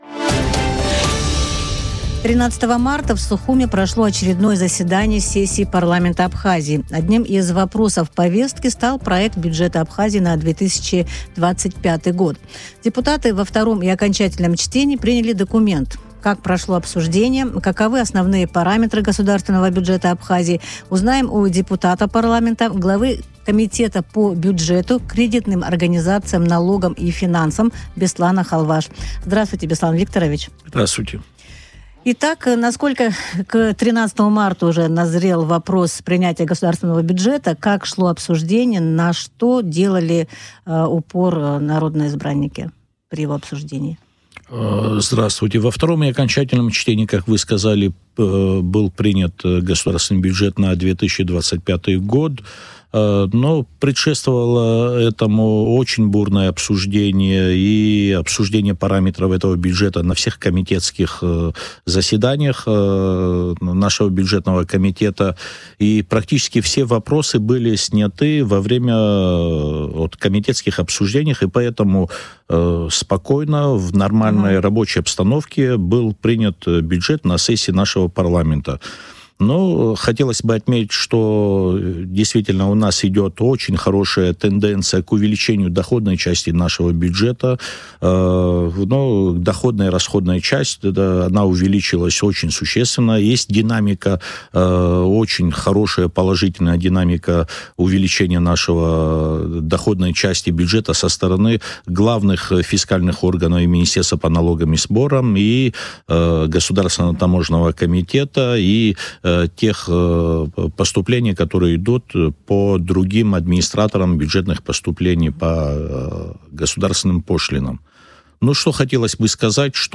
Об основных параметрах государственного бюджета Абхазии на 2025 год в эфире радио Sputnik рассказал Беслан Халваш, депутат Парламента, глава комитета по бюджету, кредитным организациям, налогам и финансам.